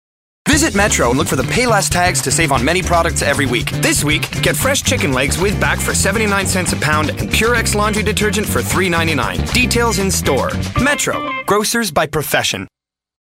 Publicité (Metro #1) - ANG